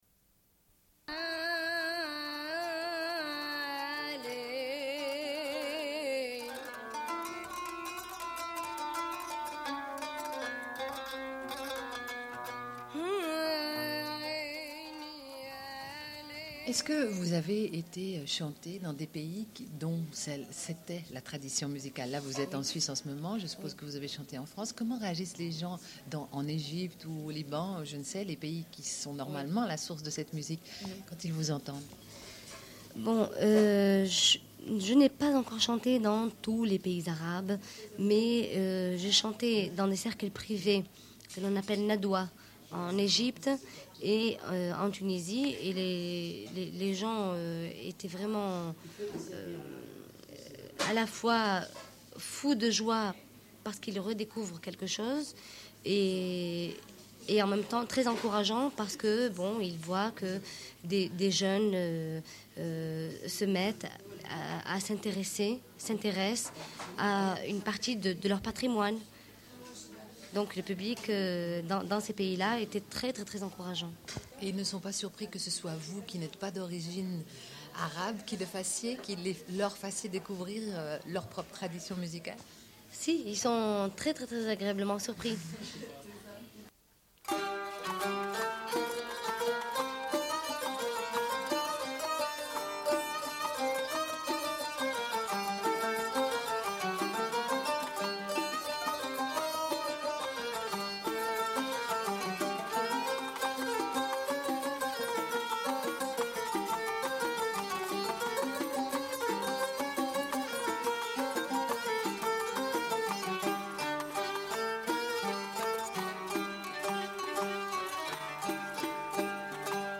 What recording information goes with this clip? Une cassette audio, face B29:01